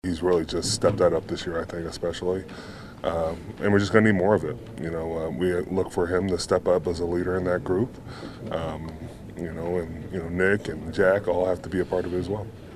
Heyward says Highsmith has taken his game to a new level this season.